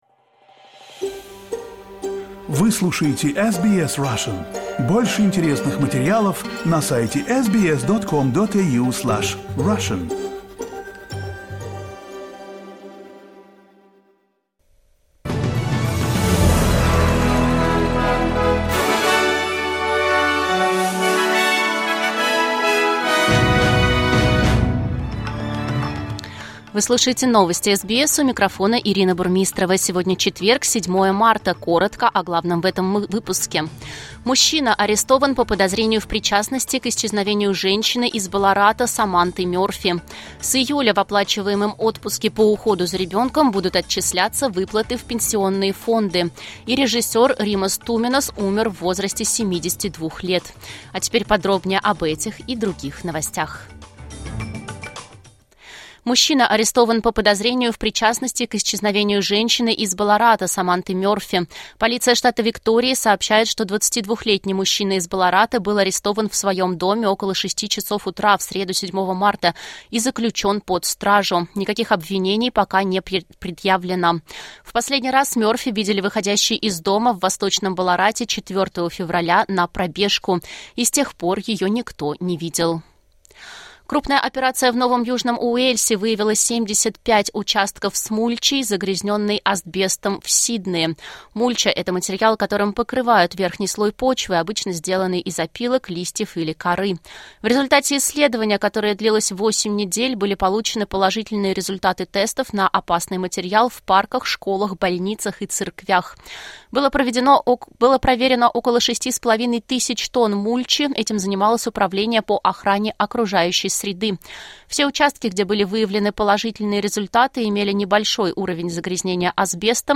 SBS news in Russian — 07.03.2024